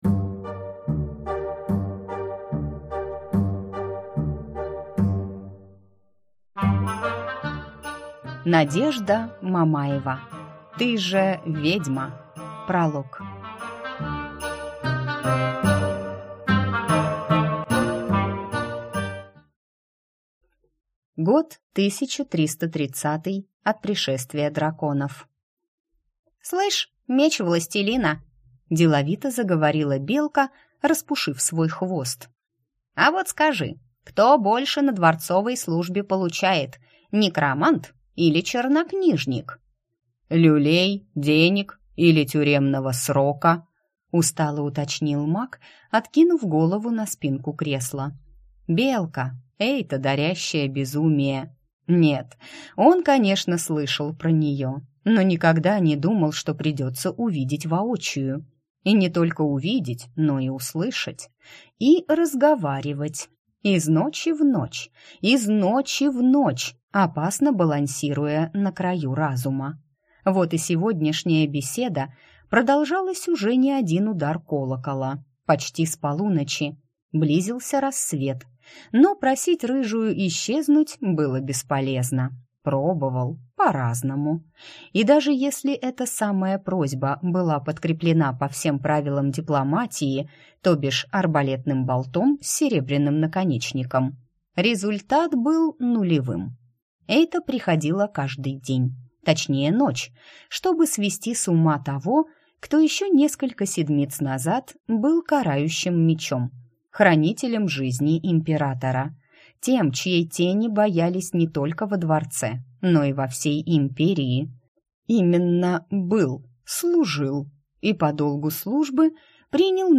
Аудиокнига Ты же ведьма!
Прослушать и бесплатно скачать фрагмент аудиокниги